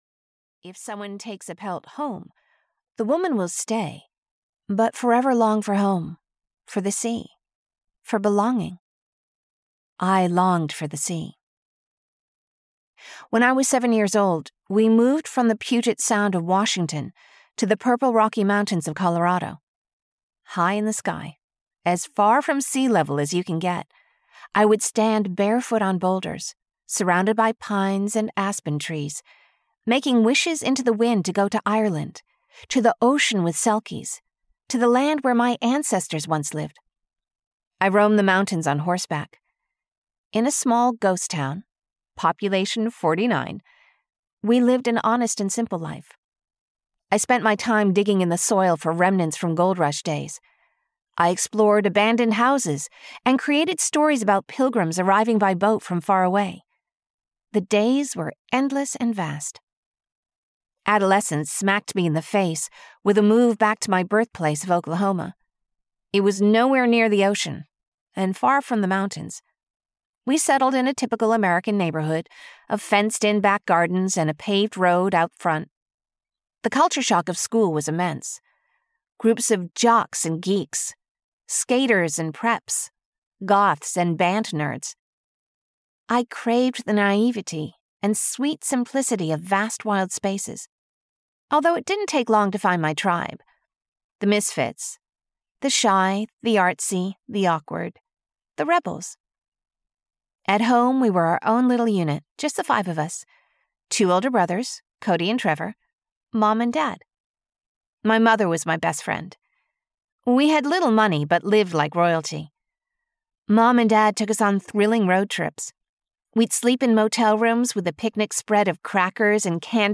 In Her Shoes (EN) audiokniha
Ukázka z knihy